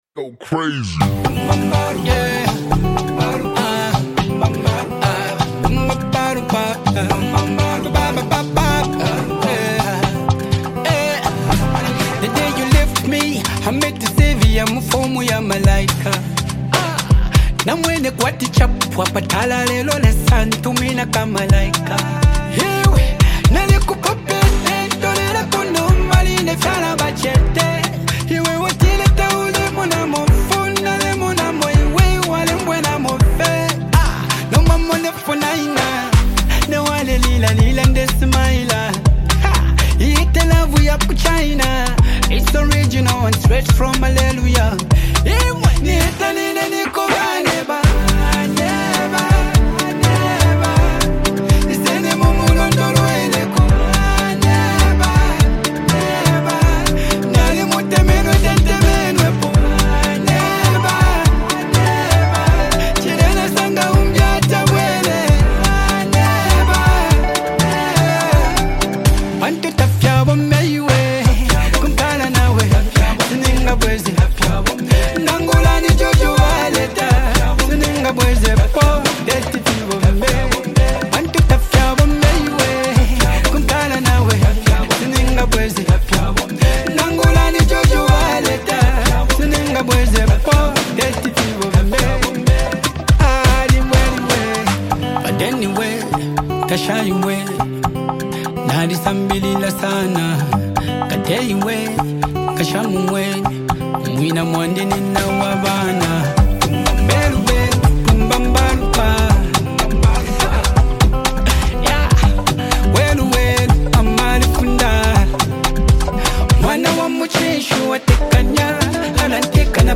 playful, relatable song
Through catchy melodies and a lighthearted tone